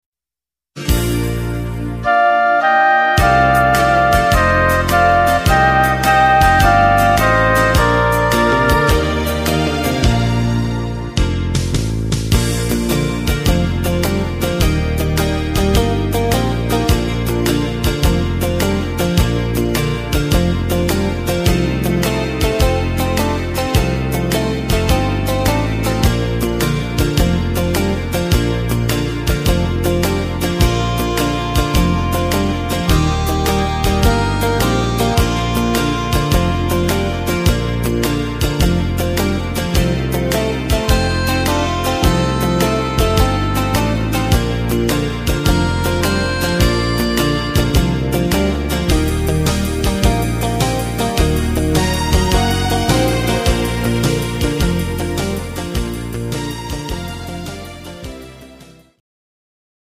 instr. Sax